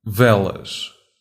Velas (Portuguese pronunciation: [ˈvɛlɐʃ]
Pt-pt_Velas_FF.ogg.mp3